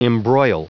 Prononciation du mot embroil en anglais (fichier audio)
Prononciation du mot : embroil